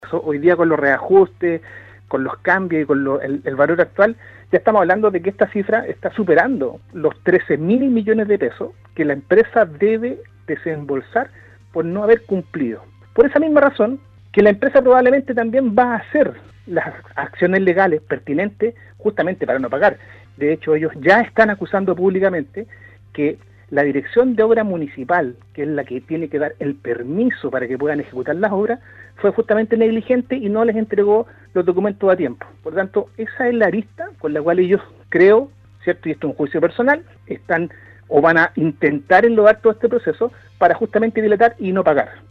En conversación con Radio Sago el concejal de Puerto Varas, Marcelo Salazar se refirió a la revocación del permiso a casino Enjoy de Puerto Varas destacando que este es un hecho inédito en el país, ya que es primera vez que se realiza un proceso revocatorio, es decir, que una empresa postula a una licitación, la gana, pero no efectúa ningún avance que tenía proyectado, por lo que se debe hacer efectivo el cobro de las boletas de garantía.